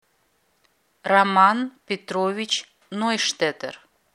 Fenerbahçe’nin yeni transferi Roman Neustädter’in telaffuzu